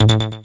游戏SFX " 浪潮02
描述：就像一个有节拍变化的波浪
Tag: 实验室 游戏 计算机 空间战 机器人 损伤 街机 激光 视频游戏